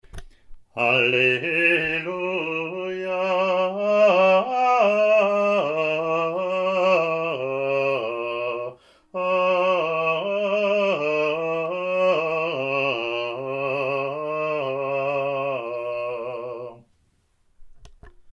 The Roman Missal gives us these Bible verses to be sung per the examples recorded: the congregation joins the cantor for the antiphon (printed), then the cantor sing the Psalm alone, then the congregation and cantor repeat the antiphon.
ot23-alleluia-gm.mp3